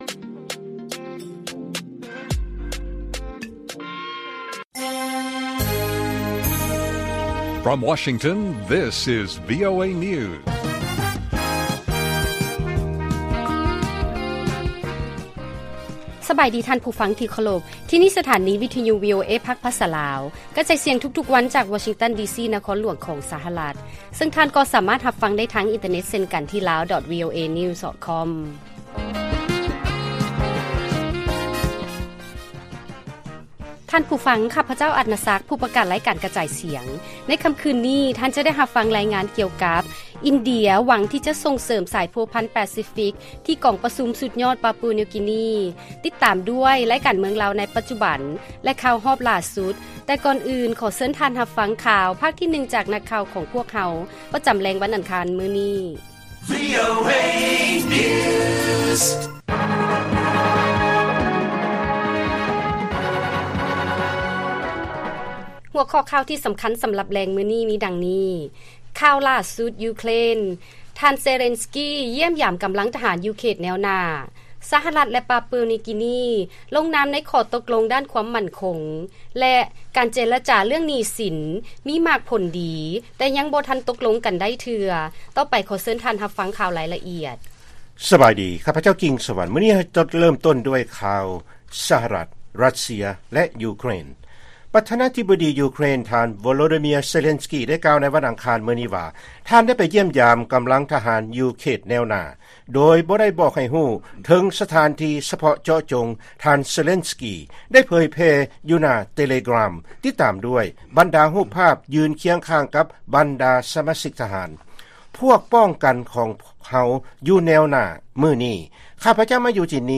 ລາຍການກະຈາຍສຽງຂອງວີໂອເອ ລາວ: ຂ່າວຫລ້າສຸດຈາກຢູເຄຣນ ທ່ານເຊເລນສ໌ກີ ຢ້ຽມຢາມກຳລັງທະຫານ ຢູ່ເຂດແນວໜ້າ